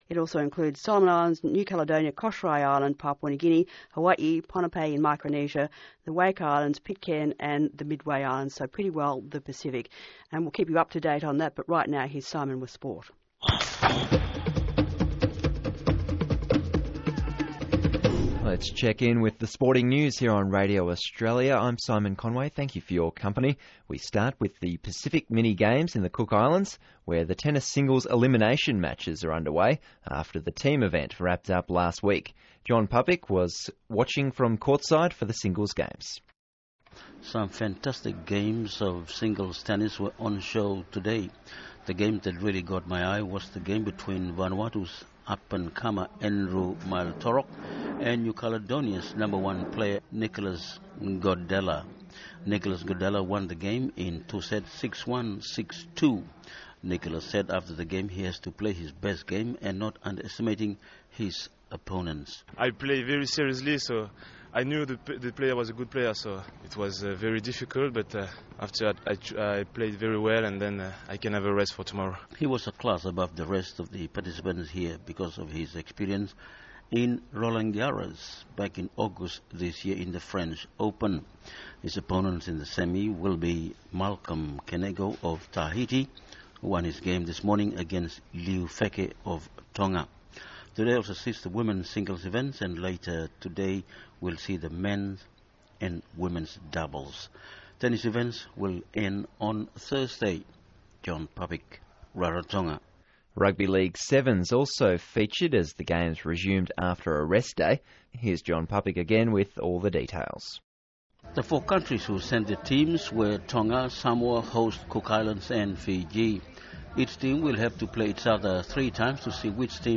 0530 TO 0600–Radio Australia’s Pacific Beat AM program of the Pacific quake and tsunami of September 30, 2009. This program went to air 68 minutes after the first wire flash of a quake off American Samoa, Samoa and Tonga.